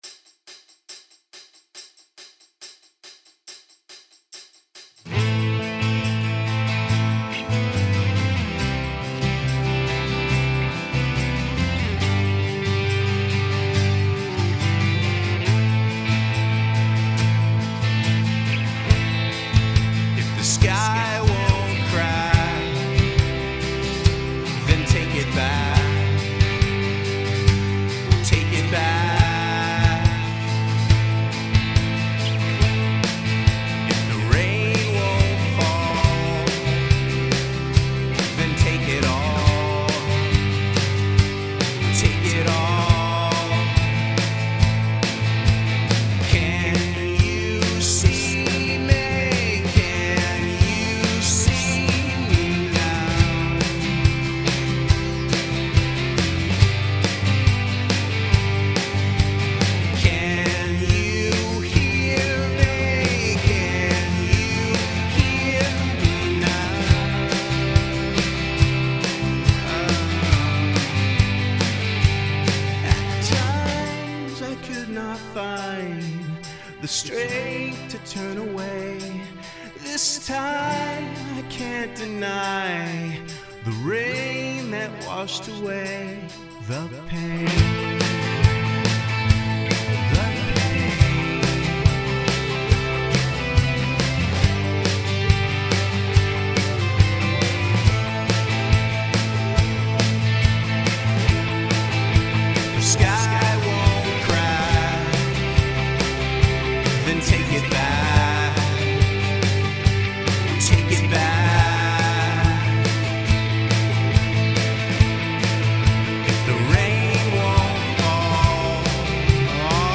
Hi all! This is a new song I laid down recently. It's not mixed well at this point. Vox are a quick one take to get the idea down, and will be totally resung.